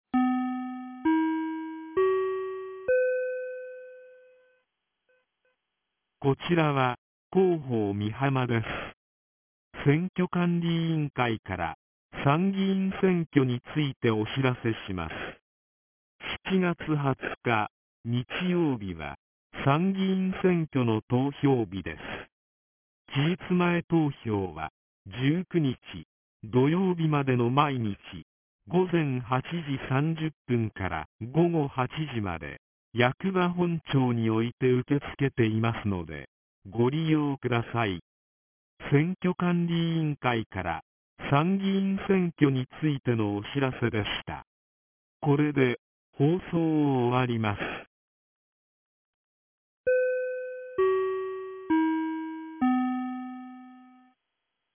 ■防災行政無線情報■ | 三重県御浜町メール配信サービス